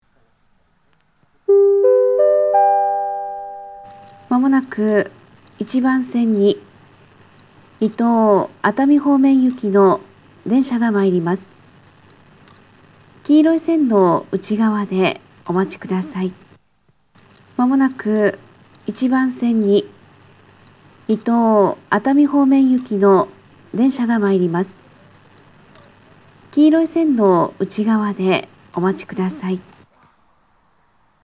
（女性）
交換のない場合や通過列車で聞けます。
上り接近放送